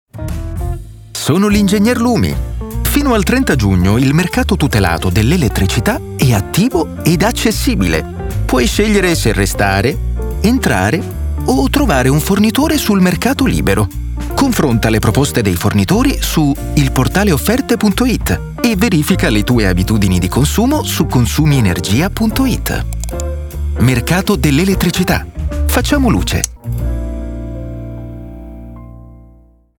Gli spot radio